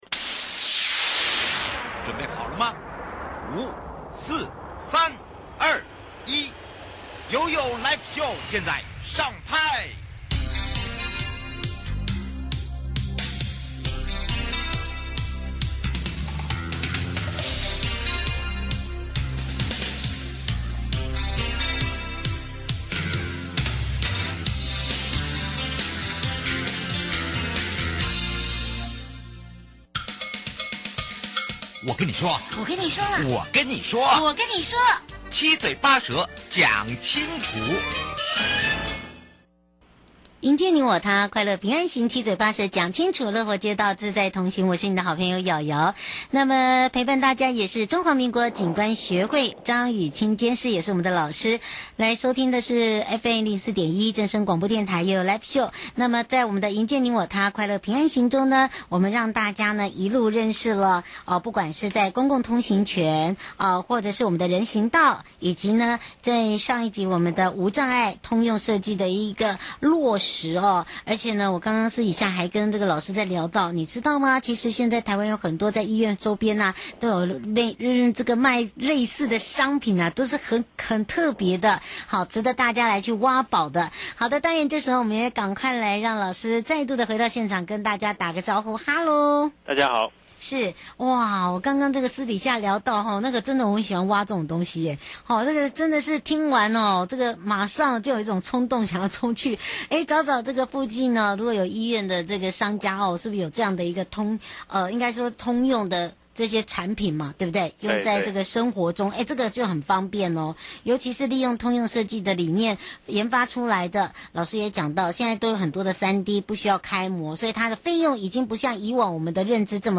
受訪者： 營建你我他 快樂平安行-此次疫情觀光業受到嚴重衝擊，請問從中獲得何種省思?